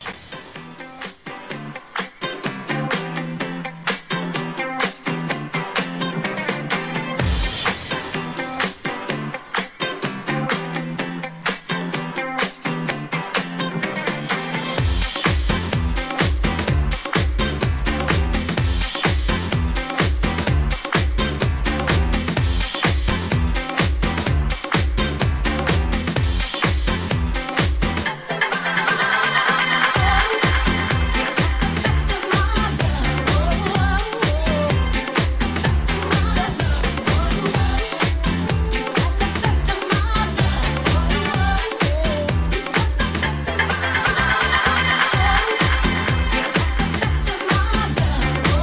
1. SOUL/FUNK/etc...
大定番ディスコ・クラシック2曲のWHITE ONLY REMIX!!